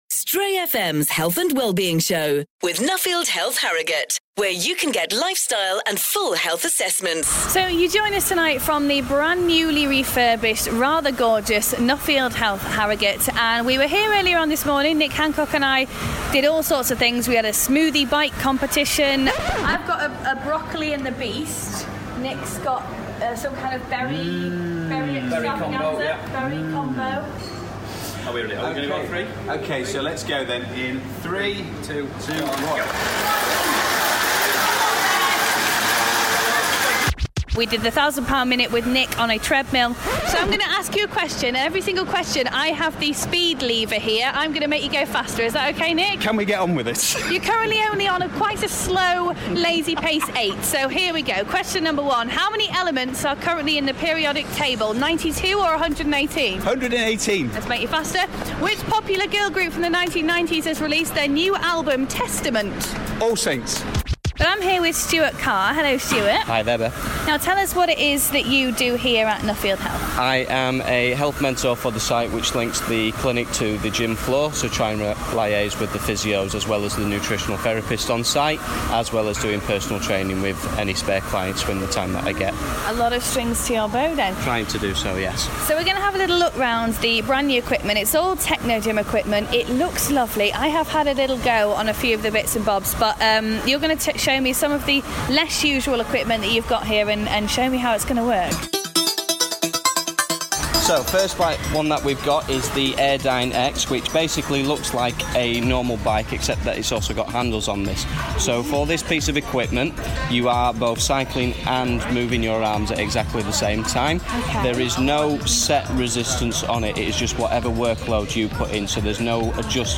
brought you the breakfast show from the newly refurbished Nuffield Health in Harrogate. They're back tonight bringing you some of the more unusual parts of the gym!